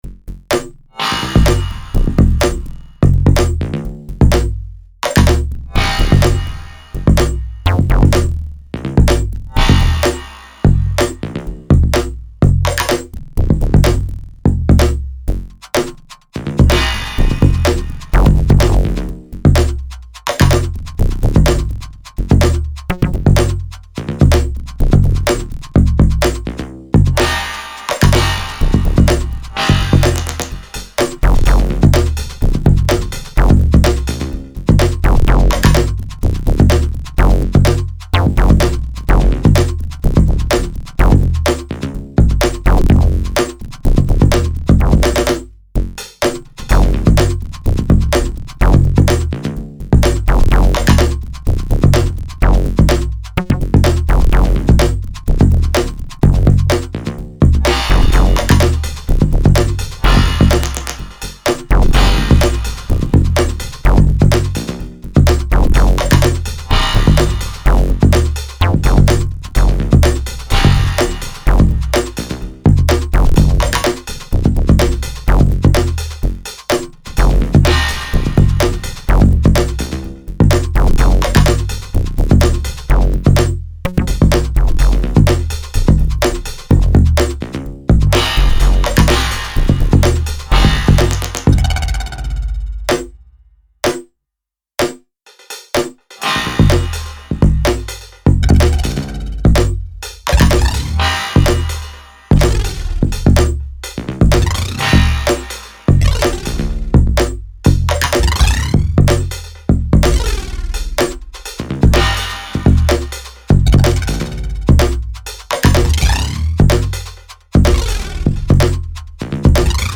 WIP Doing in SVArTracker, maybe about 1/3 done…
Jazz-Bossa sorta